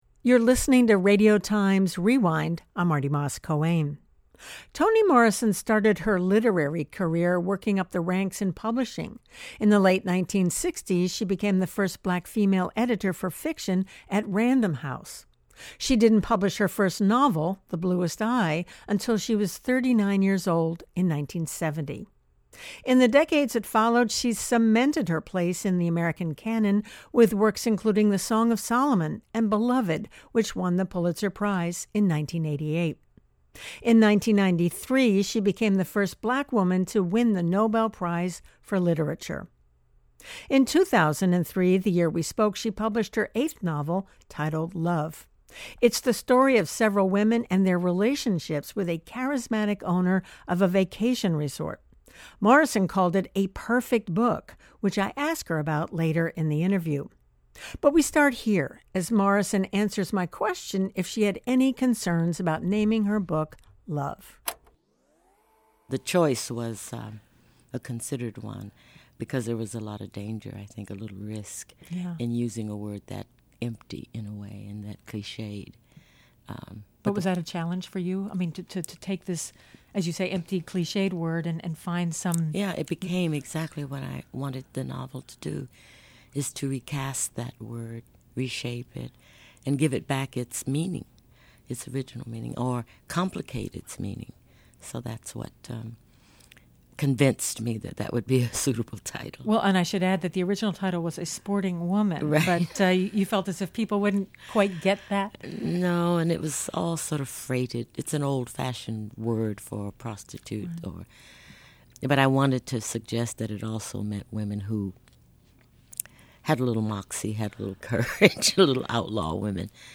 interview with Morrison to discuss the novel she had out at the time